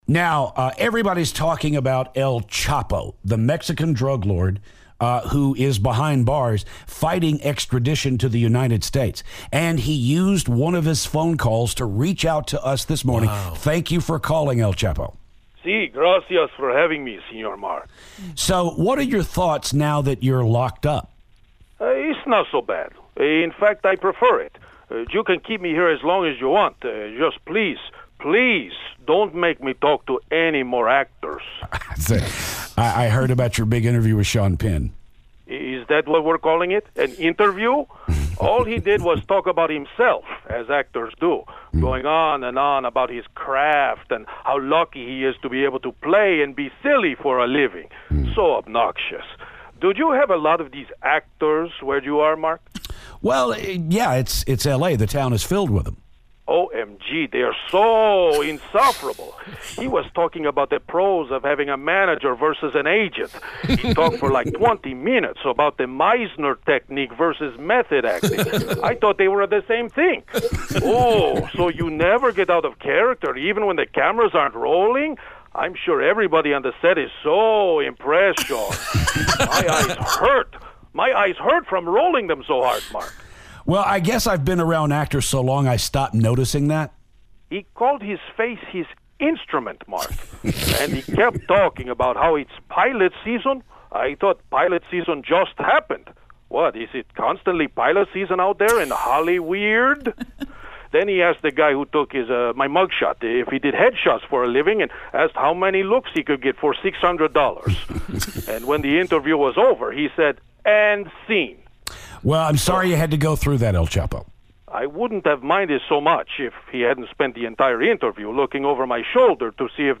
Recently arrested drug lord El Chapo calls the show.